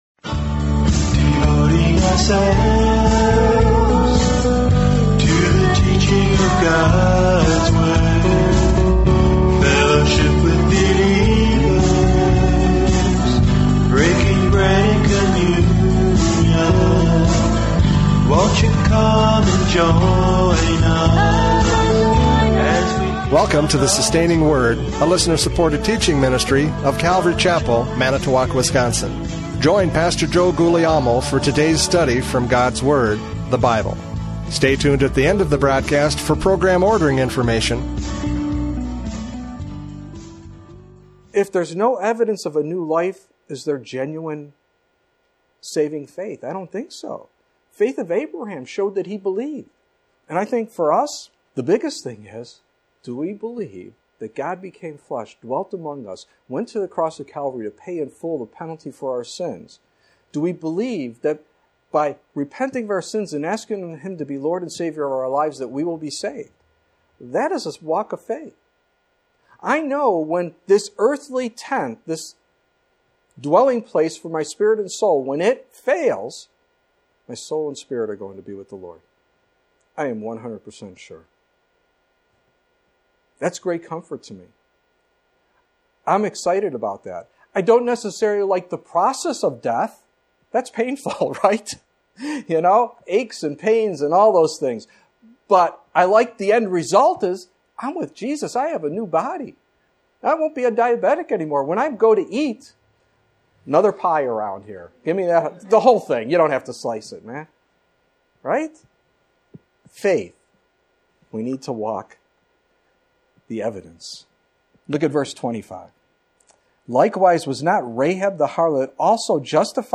James 2:21-26 Service Type: Radio Programs « James 2:21-26 Evidence for Faith!